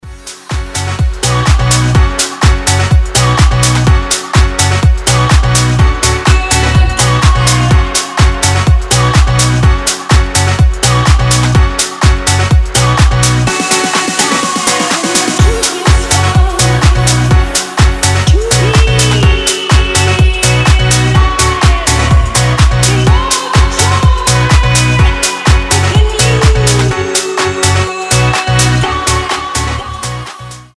ритмичные
заводные
Tech House
house
заедающие